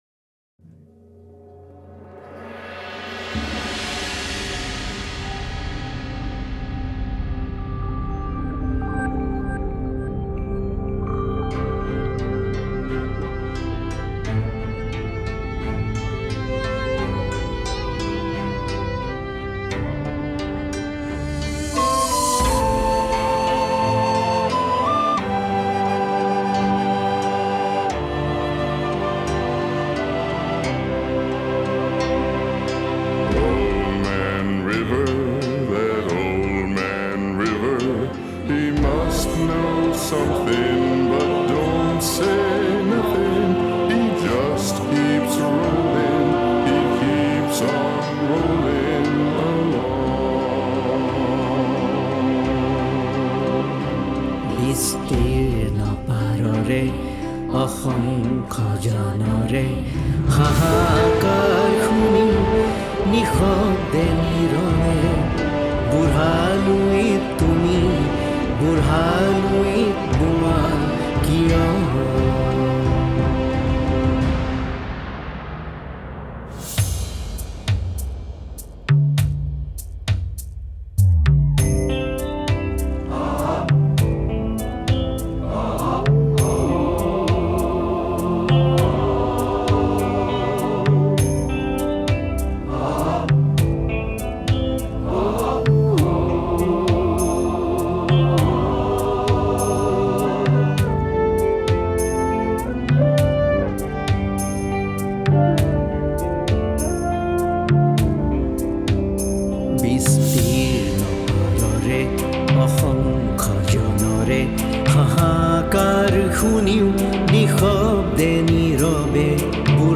recorded in the USA, India, and Ukraine